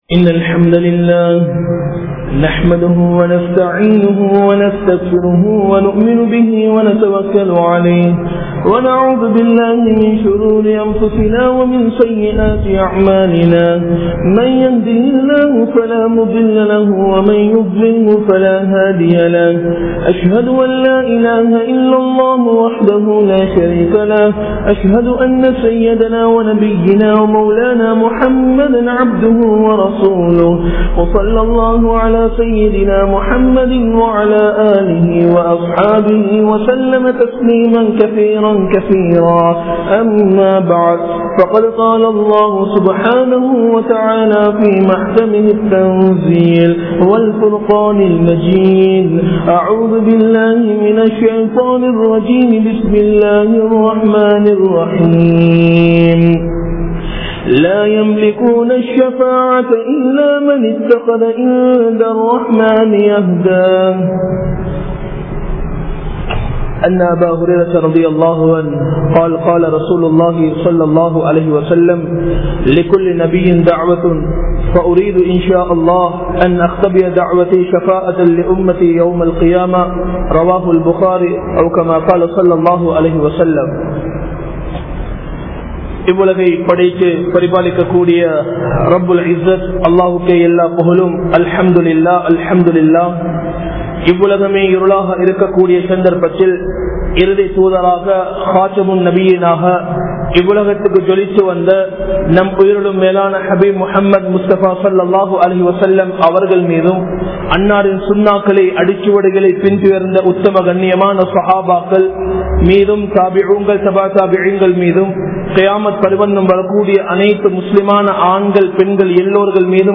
Nabi(SAW)Avarhalin Shafath Yaarukku Kidaikkum? (நபி(ஸல்)அவர்களின் ஷபாஆத் யாருக்கு கிடைக்கும்?) | Audio Bayans | All Ceylon Muslim Youth Community | Addalaichenai